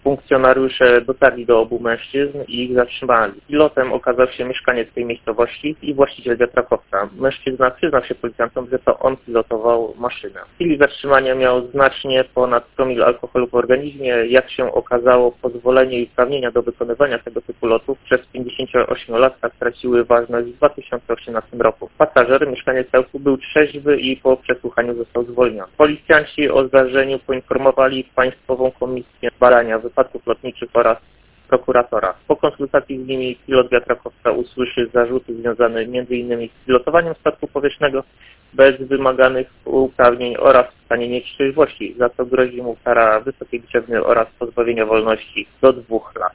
z zespołu prasowego Komendy Wojewódzkiej Policji w Olsztynie